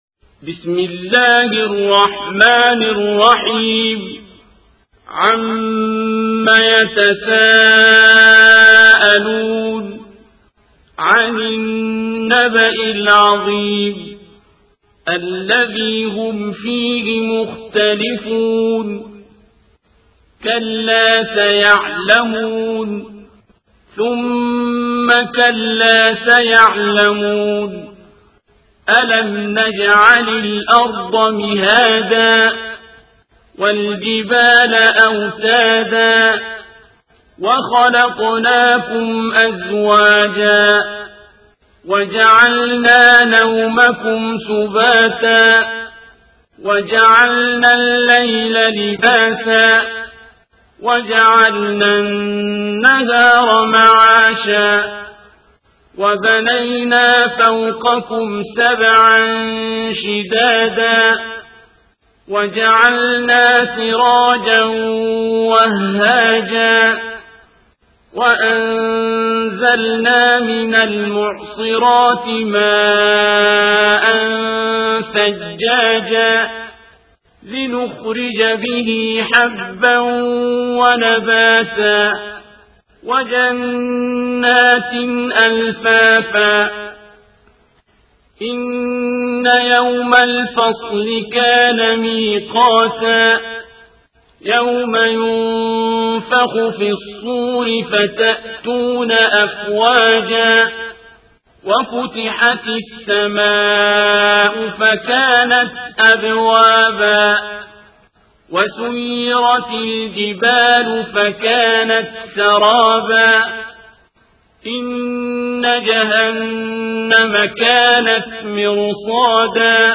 القارئ: الشيخ عبدالباسط عبدالصمد
تفاصيل : القرآن الكريم - سورة النبأ - الشيخ عبدالباسط عبدالصمد